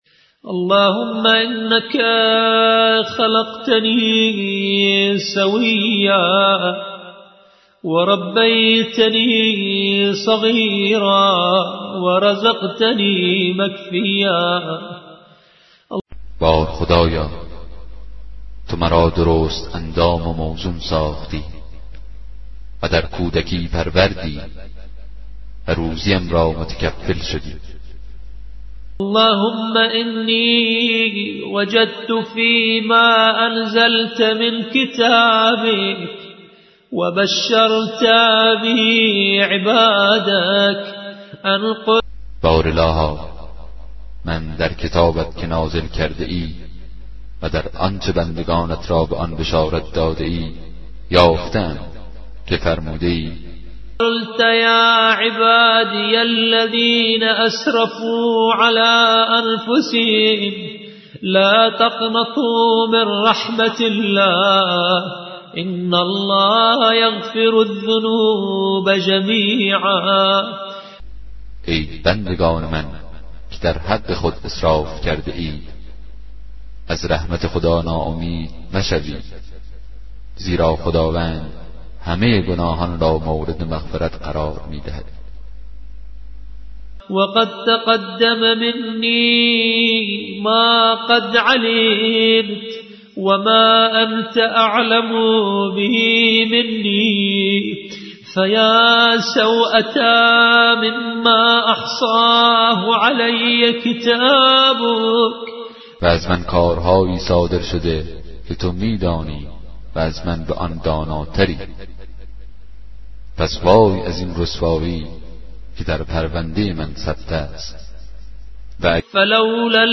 کتاب صوتی دعای 50 صحیفه سجادیه